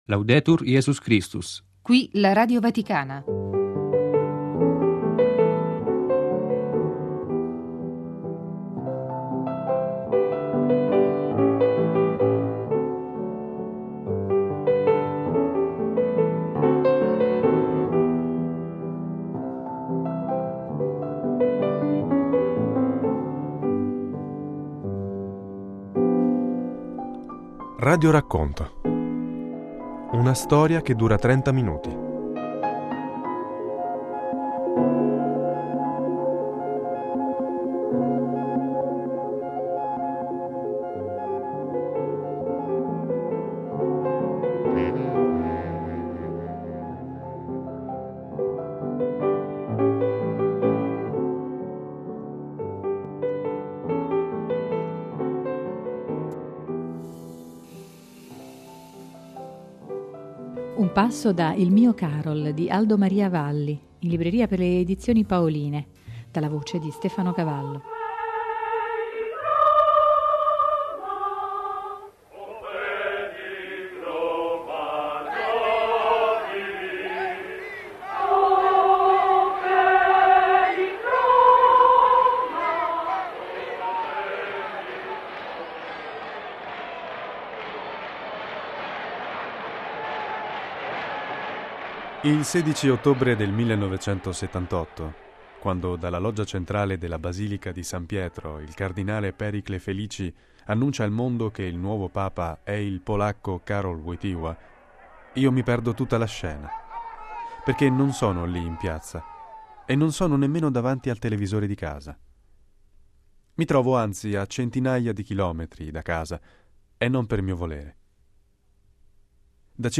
RADIORACCONTO: Il Mio Karol